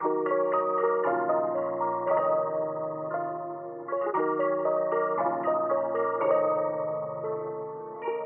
水性合成器音符1
描述：降E小调合成器Riff。洗练、昏昏欲睡、类似吉他
Tag: 116 bpm RnB Loops Synth Loops 1.39 MB wav Key : D